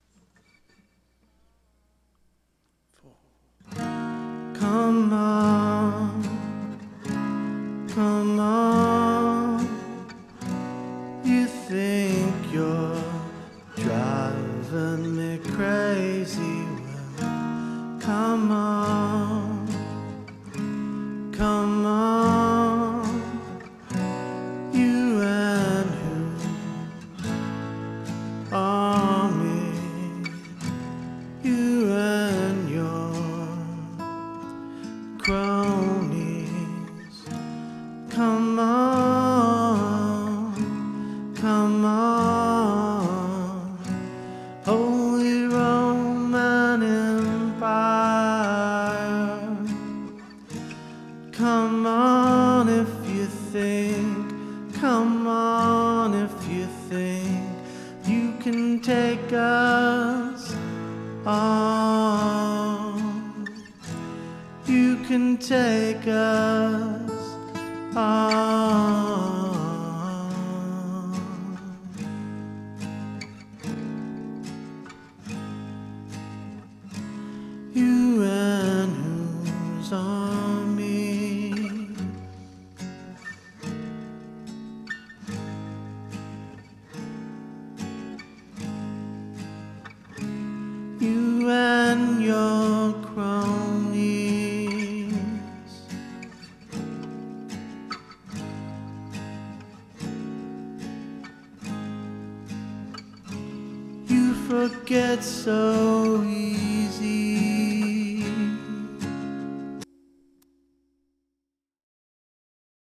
Thought it had to be dbx - it is so rich and clean.